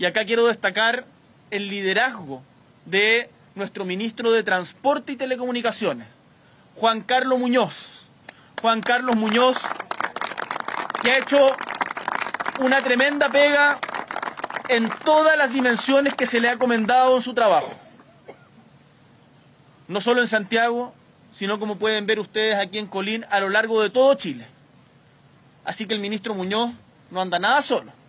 En paralelo, durante una actividad pública, el Presidente Gabriel Boric respaldó explícitamente la labor del ministro Muñoz, destacando su gestión y liderazgo.
CUNA-BORIC-.mp3